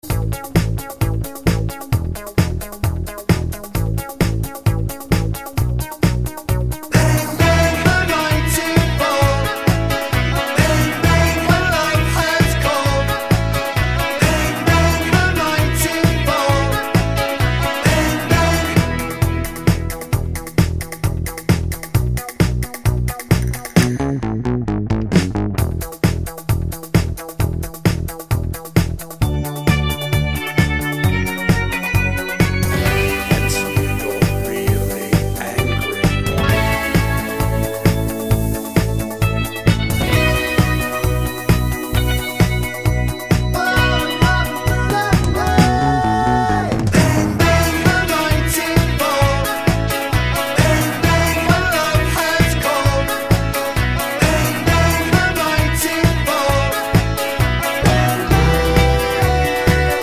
no Backing Vocals Pop (1970s) 3:23 Buy £1.50